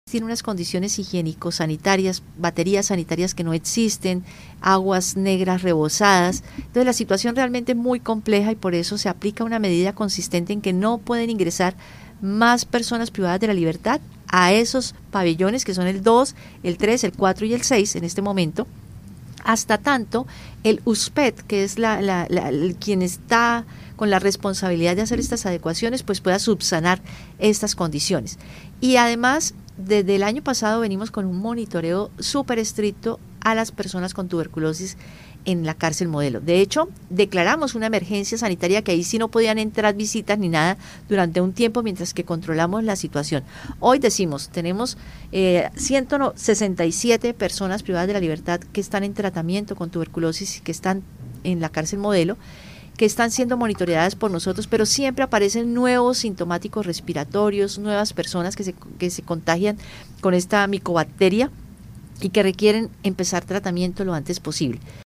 Claudia Amaya, secretaria de salud de Bucaramanga